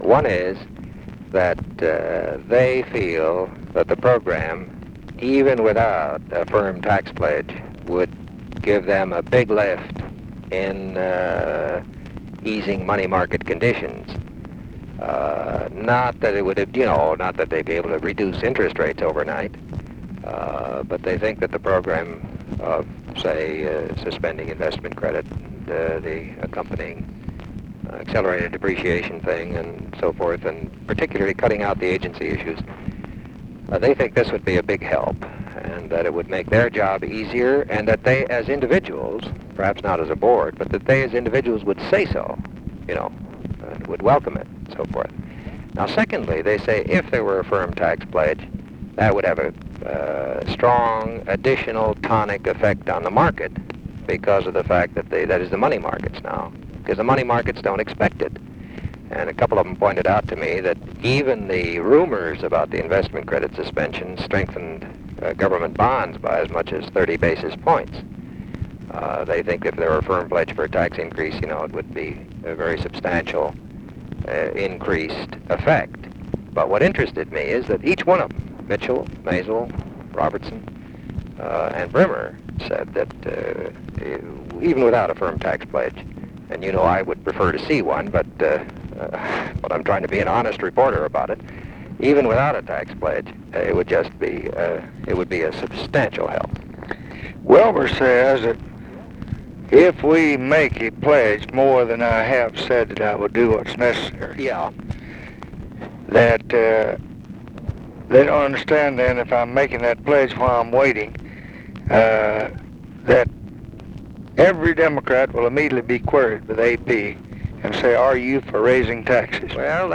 Conversation with WALTER HELLER, September 7, 1966
Secret White House Tapes